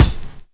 kits/RZA/Kicks/WTC_kYk (67).wav at main